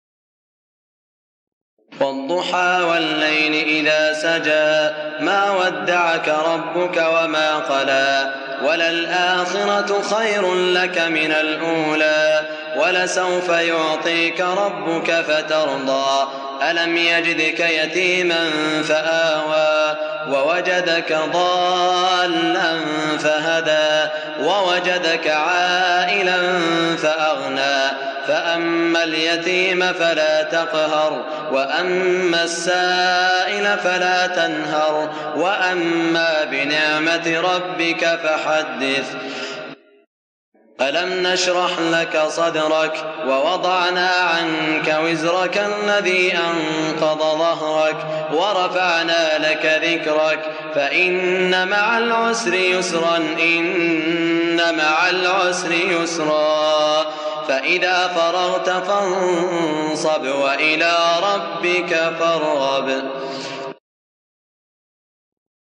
سورتي الضحى و الشرح من عام ١٤٠٨ | بمدينة الرياض > الشيخ سعود الشريم تلاوات ليست من الحرم > تلاوات وجهود أئمة الحرم المكي خارج الحرم > المزيد - تلاوات الحرمين